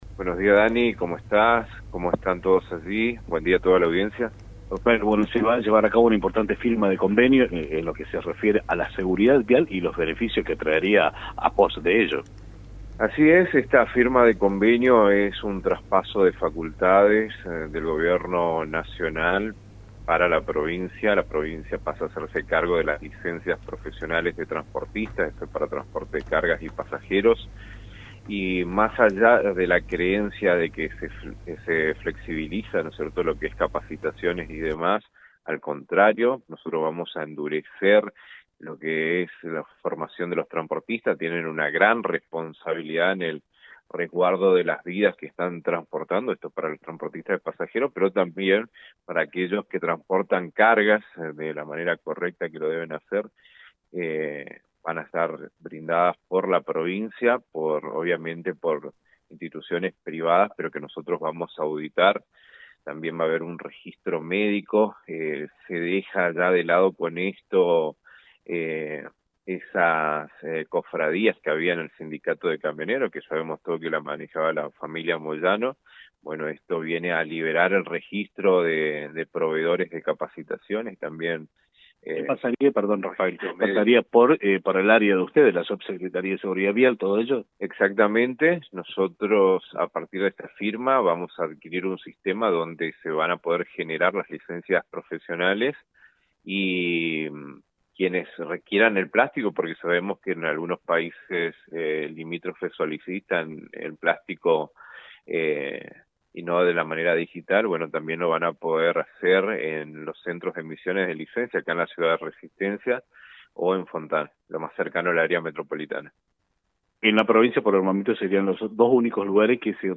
En un importante paso hacia el fortalecimiento de la seguridad vial y la transparencia institucional, el Gobierno del Chaco firmó un convenio con la Nación mediante el cual asume plenamente la facultad de otorgar licencias profesionales para conductores de transporte de carga y pasajeros. Así lo confirmó el subsecretario de Seguridad Vial, Rafael Acuña, en declaraciones radiales este lunes por la mañana.